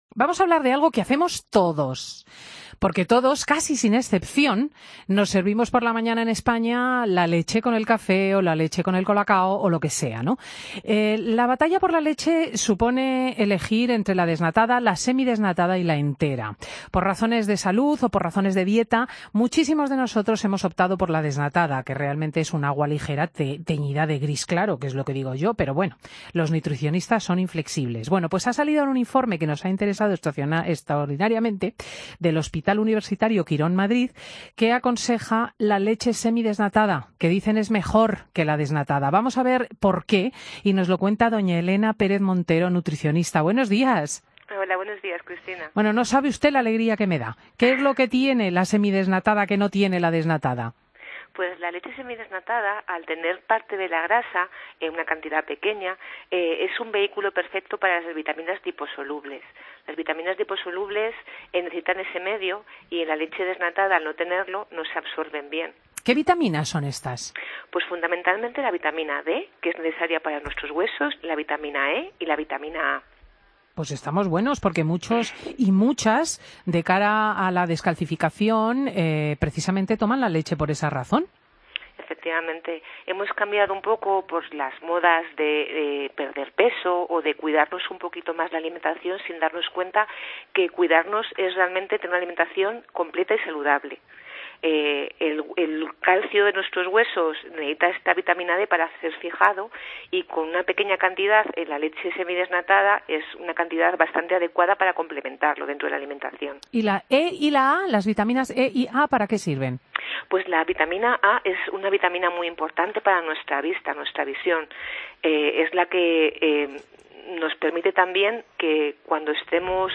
Fin de Semana Entrevista